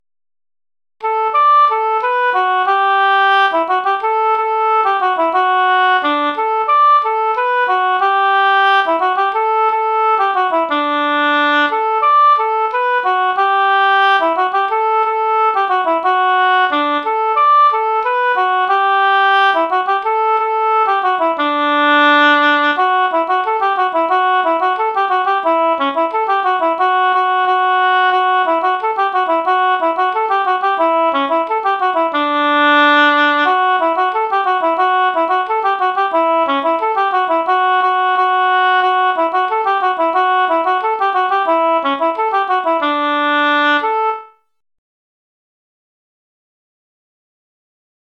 GAITA MUSIC ARCHIVE
4-Danza-da-Cruz.mp3